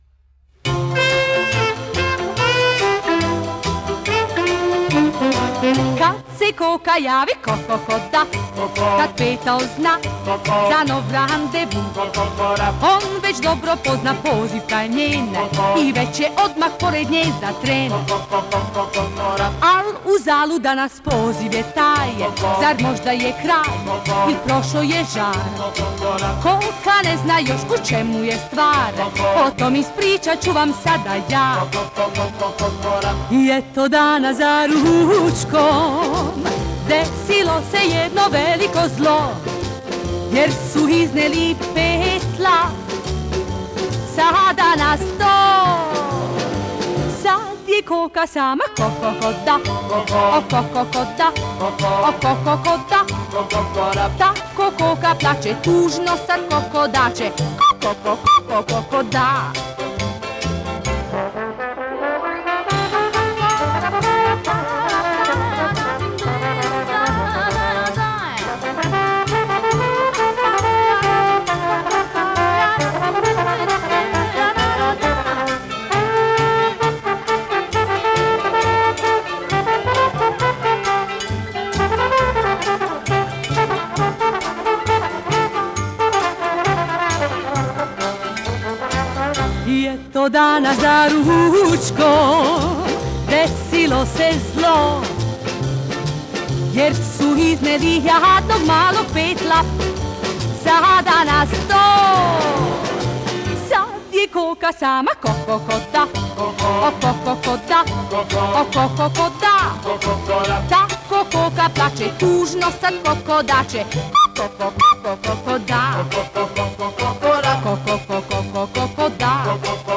югославской певицы